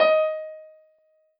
piano-ff-55.wav